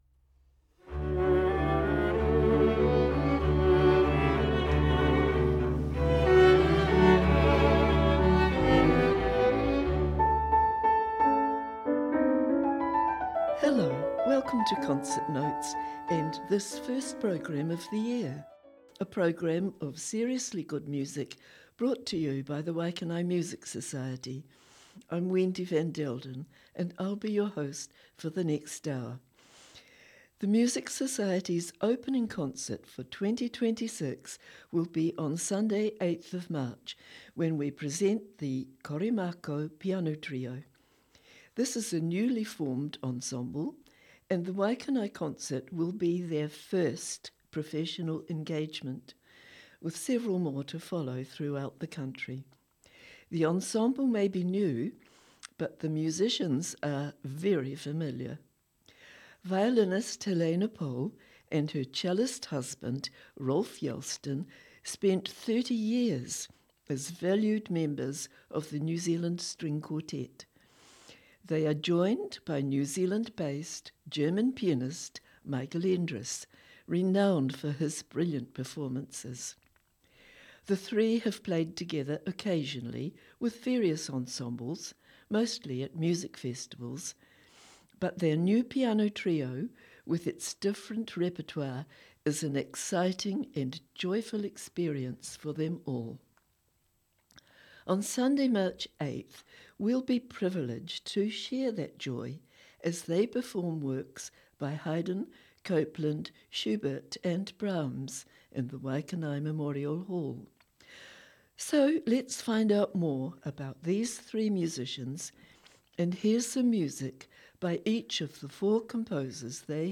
presents an hour long programme featuring the music and composers and artists in the next Waikanae Music Society Concert.